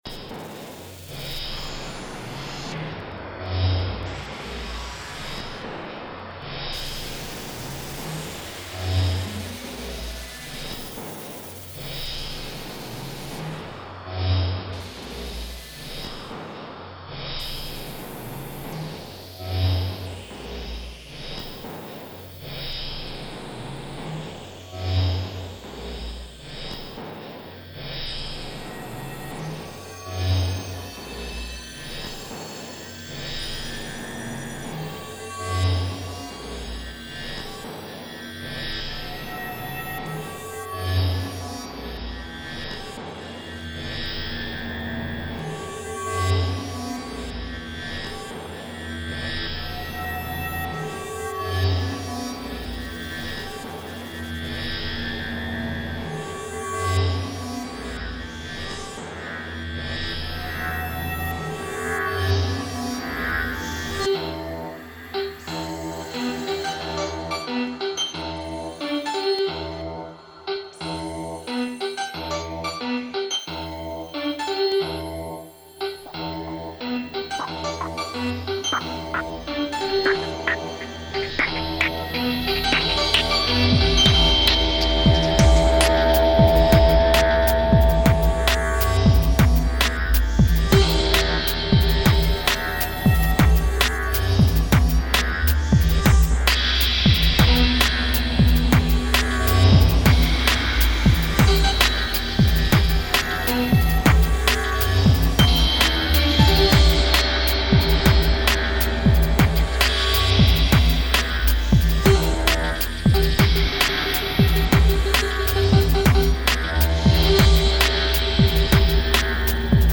style: electronica, post-hiphop, downtempo